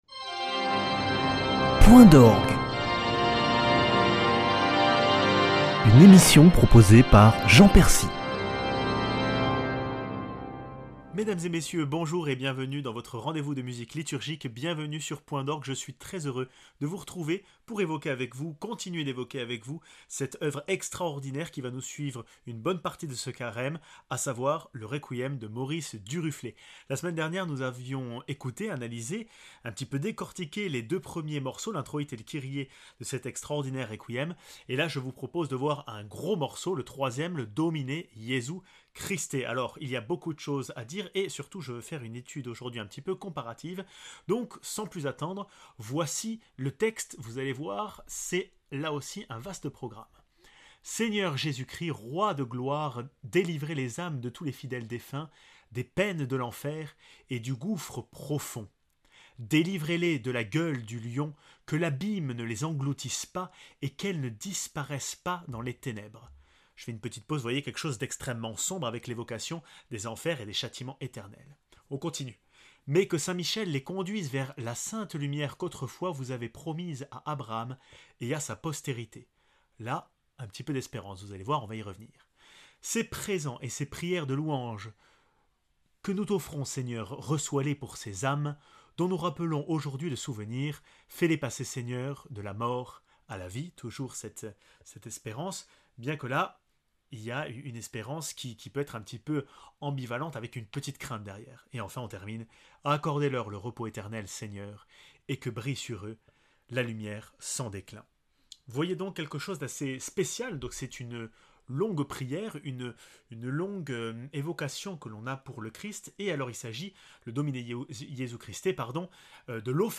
Deuxième parti de ce Requiem avec une écoute comparative entre la version pour orgue seul et la version avec orchestre.